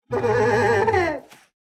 Minecraft Version Minecraft Version 1.21.5 Latest Release | Latest Snapshot 1.21.5 / assets / minecraft / sounds / mob / sniffer / happy3.ogg Compare With Compare With Latest Release | Latest Snapshot
happy3.ogg